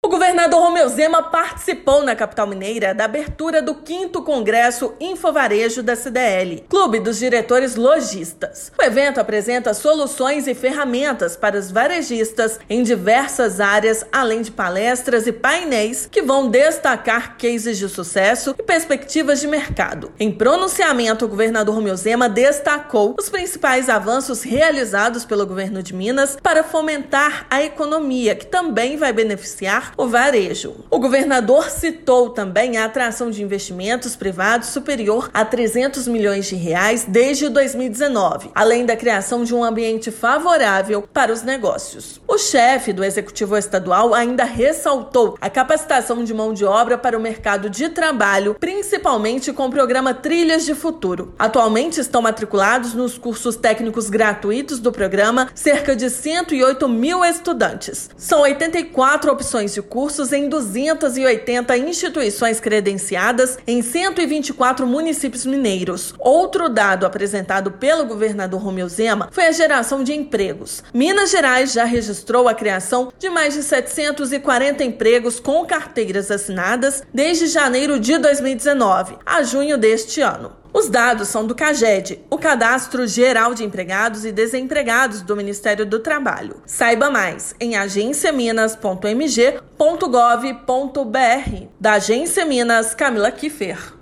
Durante abertura do quinto Congresso Infovarejo CDL/BH, governador apresentou indicadores que demonstram que Minas é um estado acolhedor para quem pretende empreender. Ouça matéria de rádio.